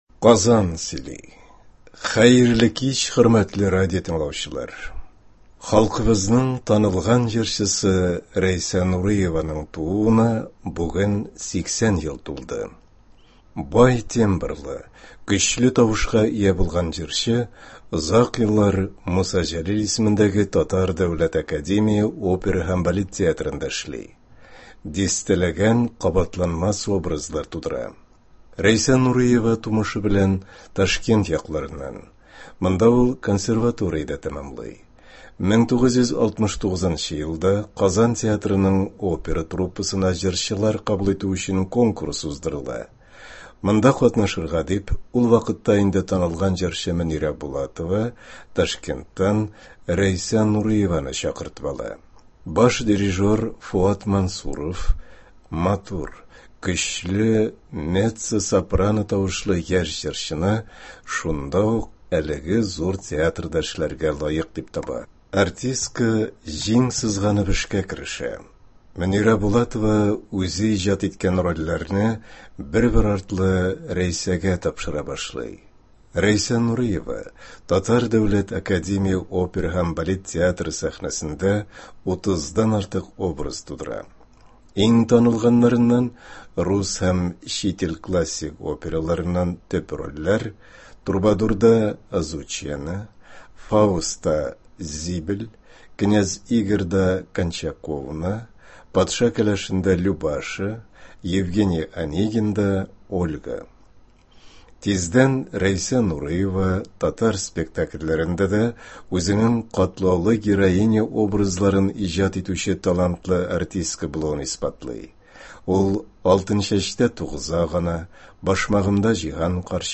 Бай тембрлы, көчле тавышка ия булган җырчы озак еллар